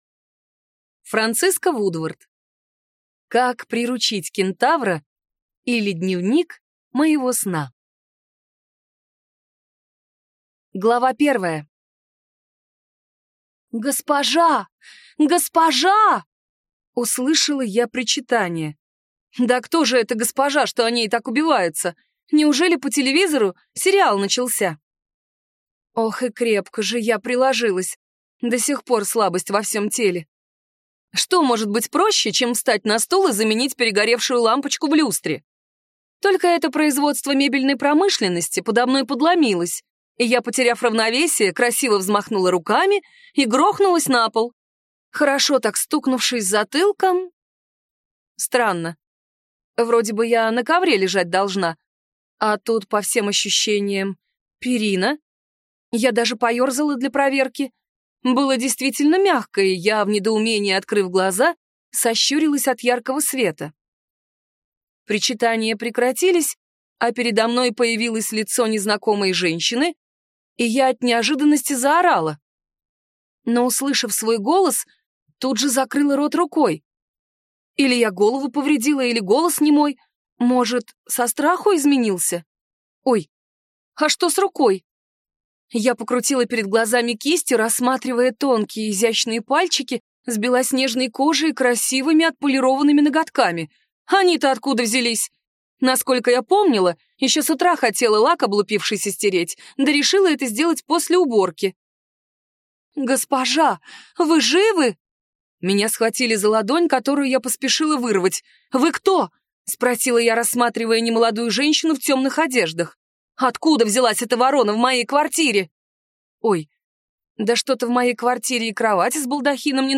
Аудиокнига Как приручить кентавра, или Дневник моего сна | Библиотека аудиокниг